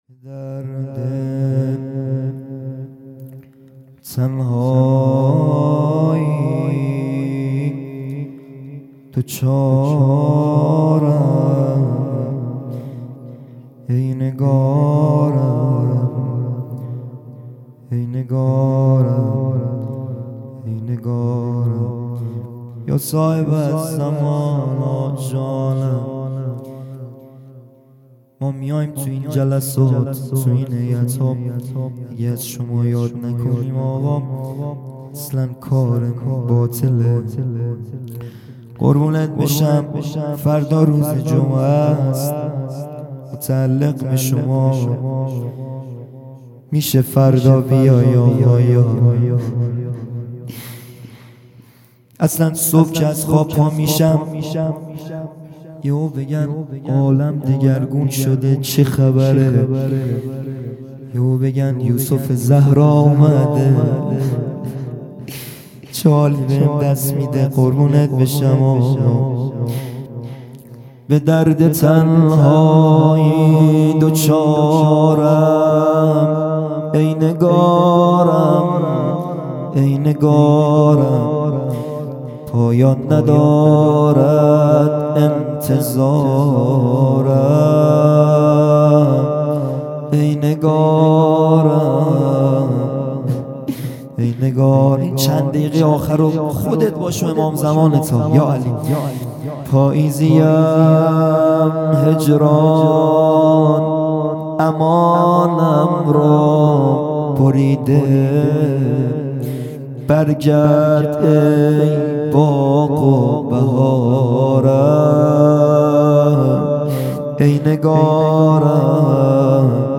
مناجات پایانی | به درد تنهایی دچارم
جلسۀ هفتگی | به مناسبت شهادت حضرت معصومه(س) | ‍به یاد شهید آیت الله حسن مدرس | 14 آذر 1398